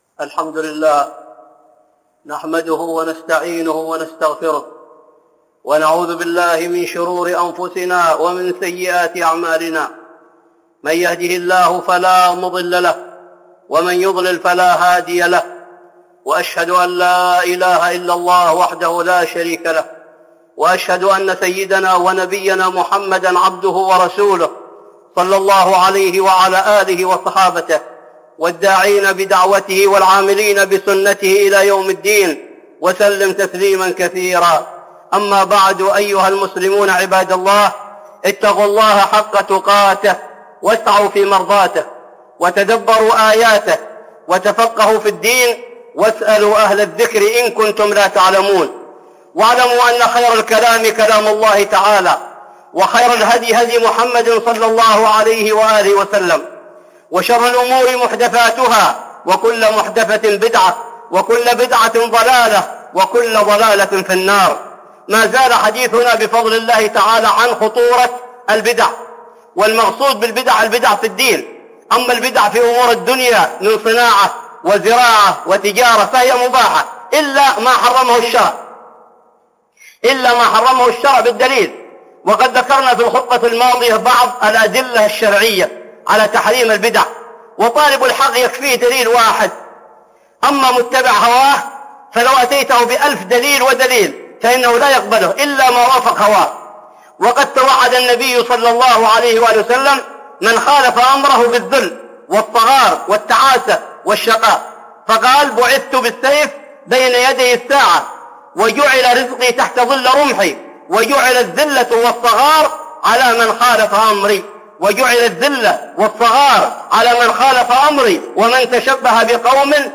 (خطبة جمعة) بعنوان (البدع) والثانية (كفر النعمه)